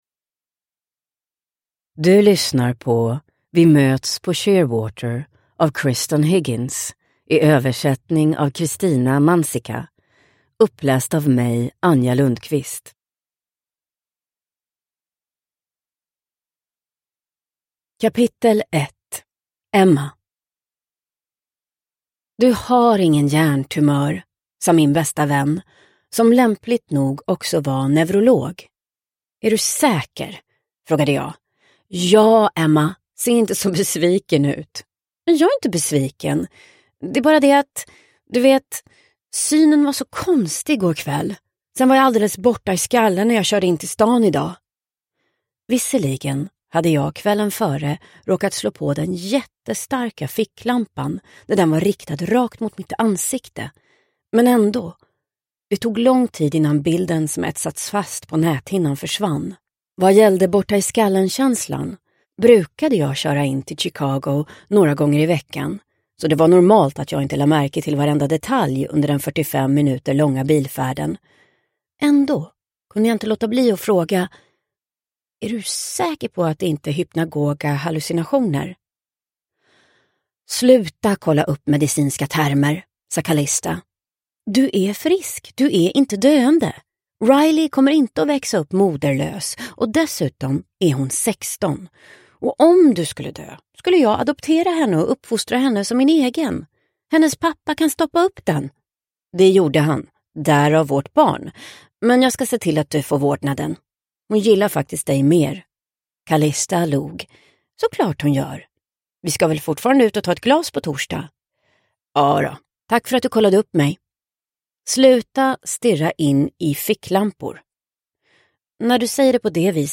Vi möts på Sheerwater – Ljudbok – Laddas ner